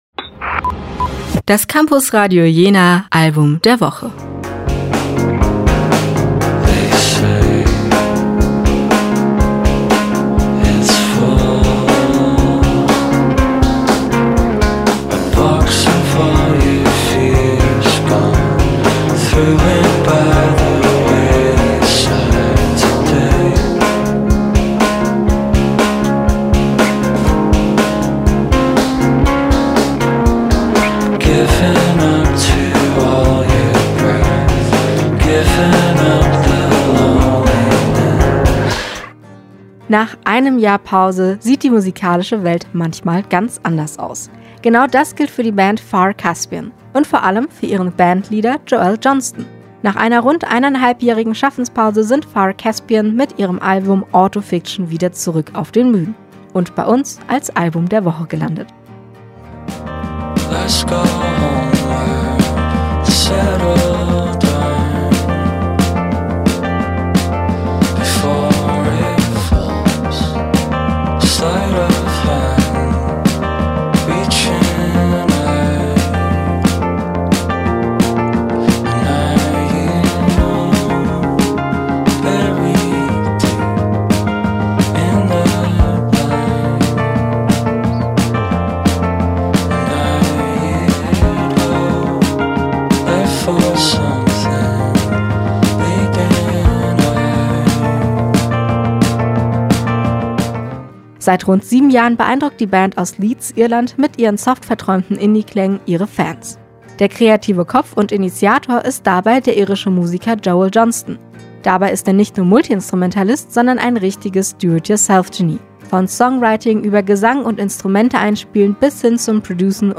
Ein Album voller Oden an den sanften Indie-Pop